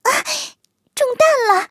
卡尔臼炮小破语音1.OGG